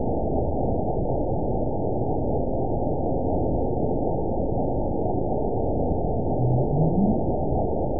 event 922768 date 03/29/25 time 08:08:55 GMT (2 months, 2 weeks ago) score 9.37 location TSS-AB04 detected by nrw target species NRW annotations +NRW Spectrogram: Frequency (kHz) vs. Time (s) audio not available .wav